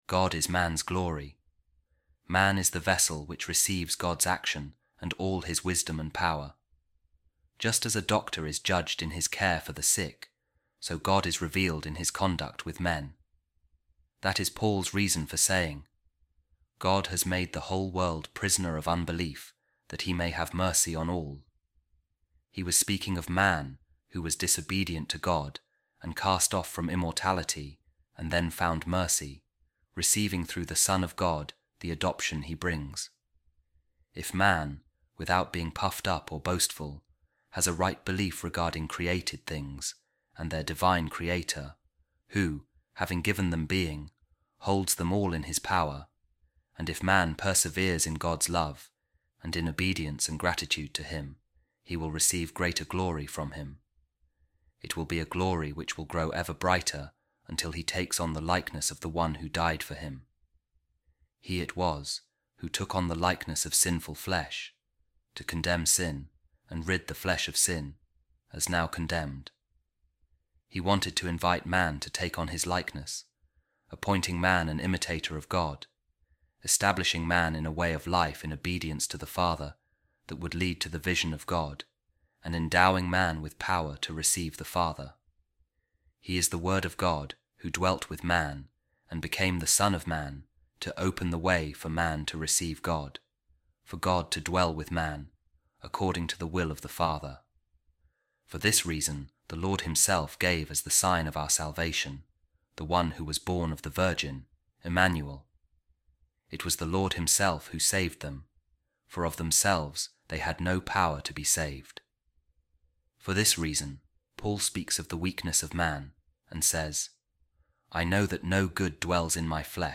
A Reading From The Treatise Of Saint Iranaeus Against The Heresies | The Operation Of The Redeeming Incarnation